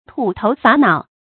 發音讀音
tù tóu suō nǎo